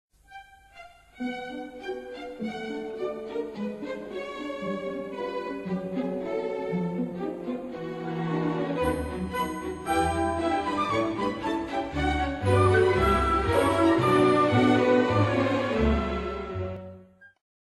Suite in 4 Sätzen für Salonorchester oder Großes Orchester
Besetzung: variabel – Großes Orchester, Salonorchester